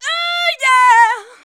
UUU YEAH.wav